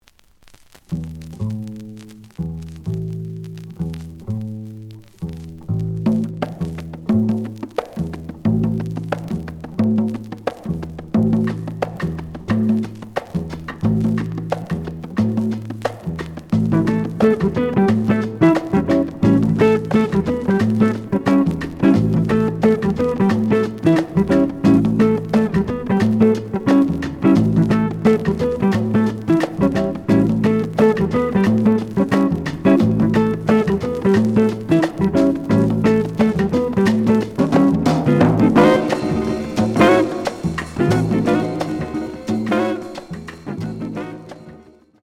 The audio sample is recorded from the actual item.
●Genre: Jazz Funk / Soul Jazz
Looks good, but slight noise on both sides.